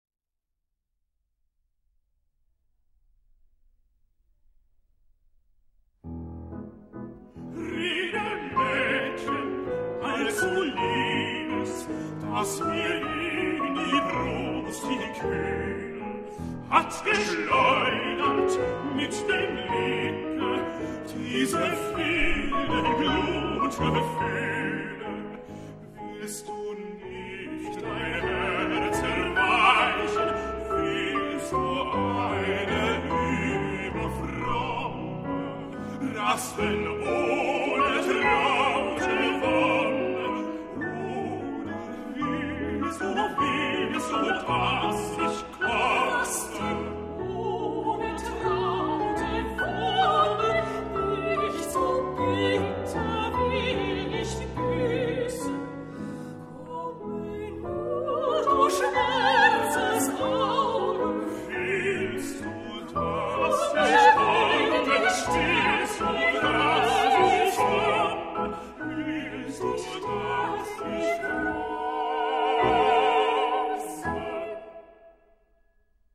谱成混声四部(加上独唱者)的合唱曲
以四手联弹钢琴伴奏